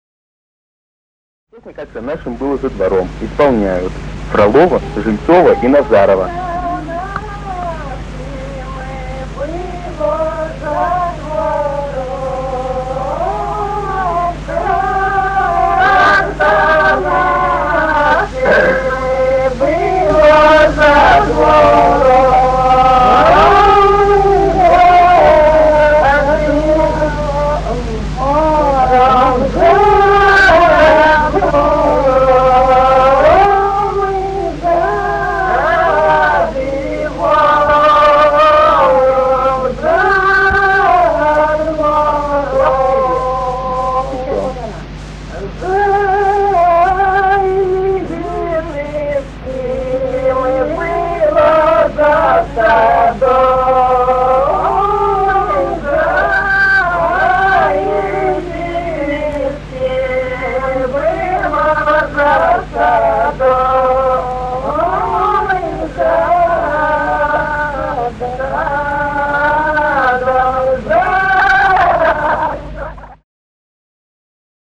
Русские народные песни Владимирской области 35. Как за нашим было за двором (хороводная) с. Михали Суздальского района Владимирской области.
Прим.: «игровая хороводная» (НЦНМ.